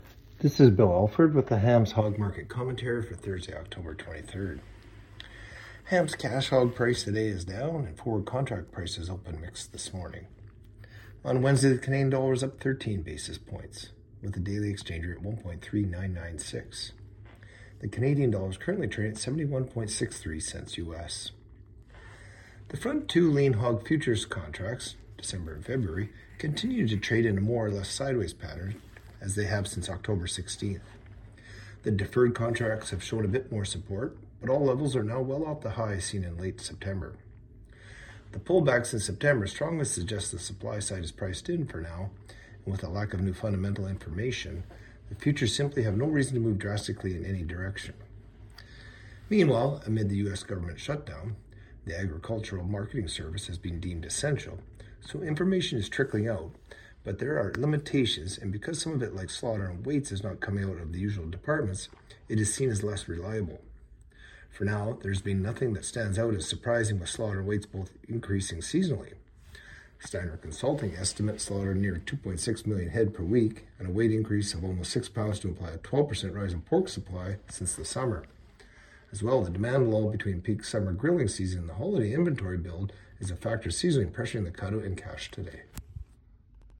Hog-Market-Commentary-Oct.-23-25.mp3